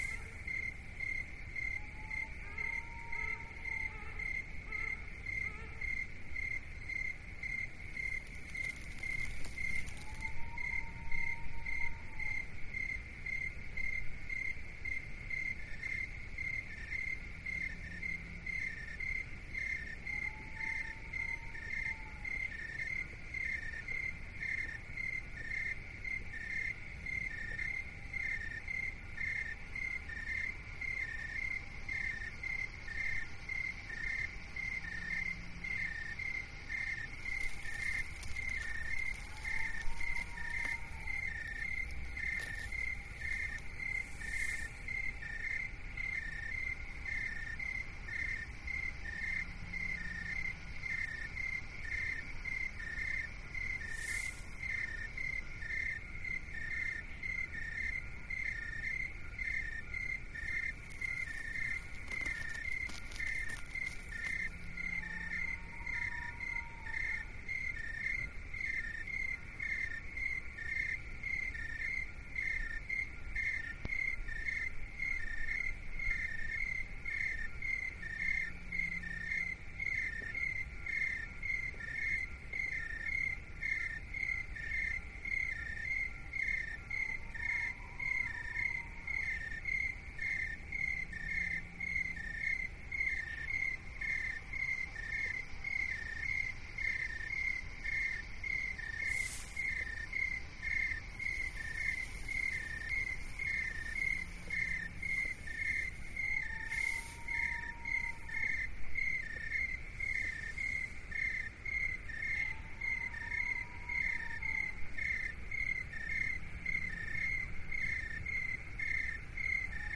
Звуки ночи
Чистый звук ночной атмосферы второй версии